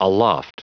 Prononciation du mot aloft en anglais (fichier audio)
Prononciation du mot : aloft
aloft.wav